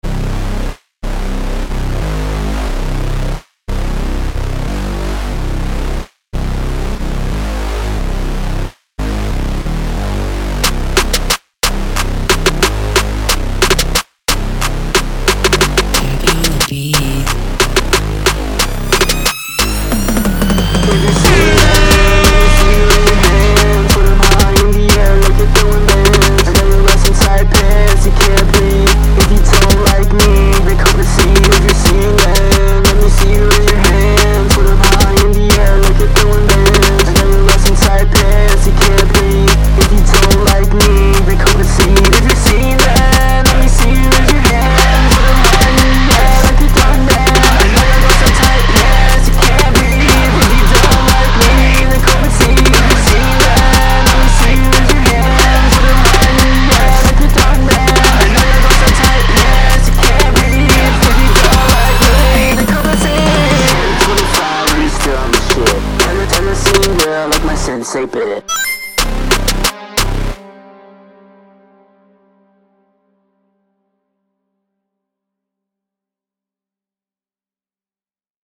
crunkcore x jerk inspired song. i think the beat is pretty good, but the vocals could use some work. i was just trying to see what stuck, tbh.
scene x jerk beat.mp3